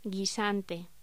Locución: Guisante